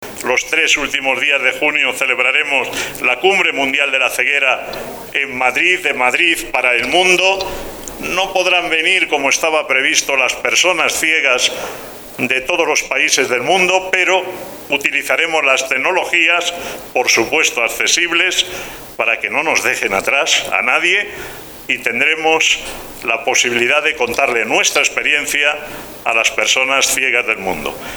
anunció ante todos los mandatarios formato MP3 audio(0,53 MB), la celebración en Madrid el próximo mes verano del  'World Blindness Summit'Abre Web externa en ventana nueva, la Cumbre Mundial de la Ceguera, que reunirá en la capital española a los mayores expertos y organismos mundiales relacionas con la discapacidad visual, del 28 al 30 de junio.